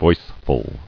[voice·ful]